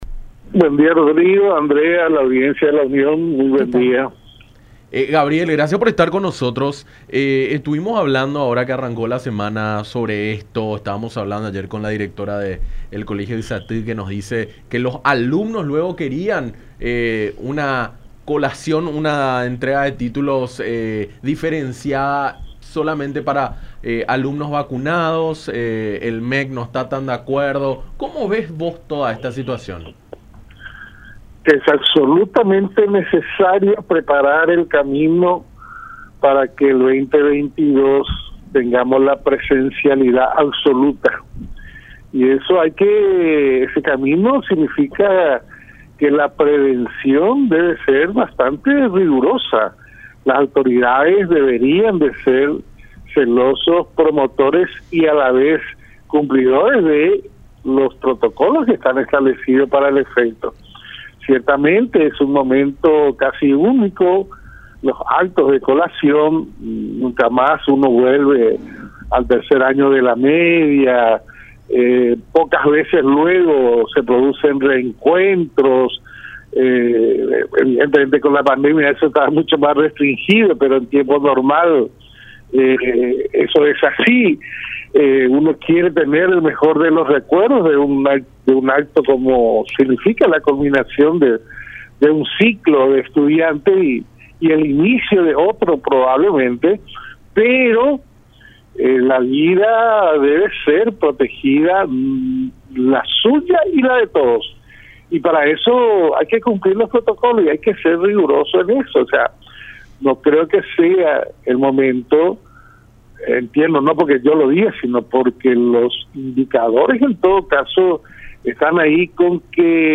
en diálogo con Enfoque 800 a través de La Unión